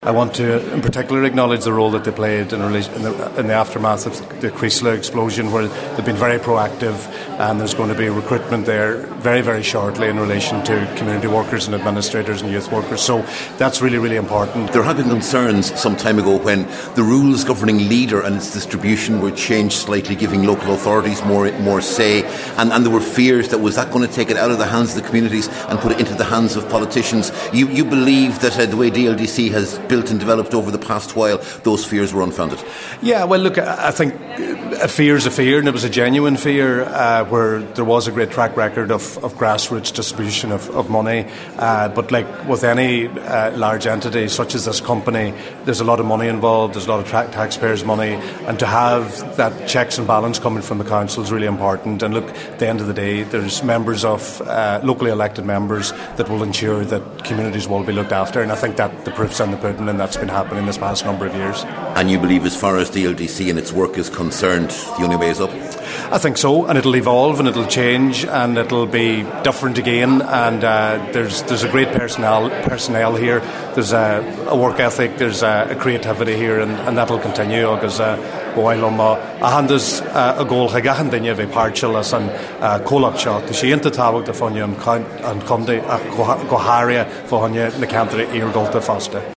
Deputy Joe McHugh was speaking after the opening of DLDC’s new offices this week by Minister Heather Humphries.